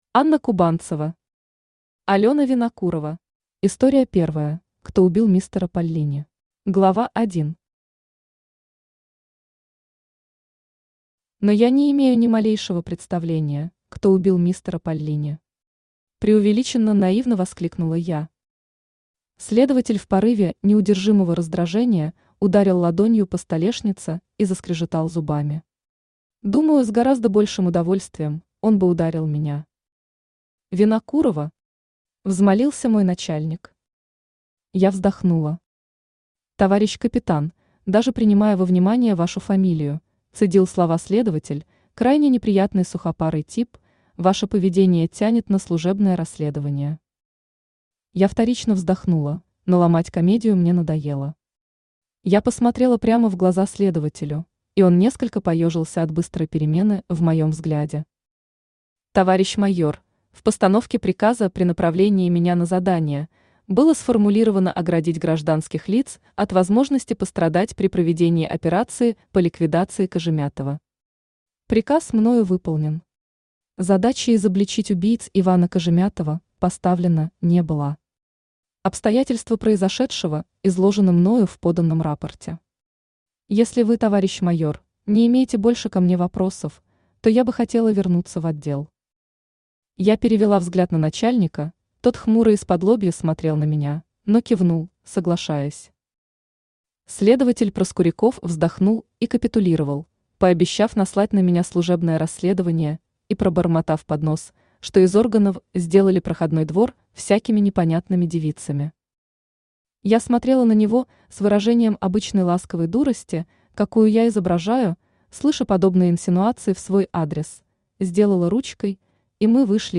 Аудиокнига Алена Винокурова | Библиотека аудиокниг
Aудиокнига Алена Винокурова Автор Анна Сергеевна Кубанцева Читает аудиокнигу Авточтец ЛитРес.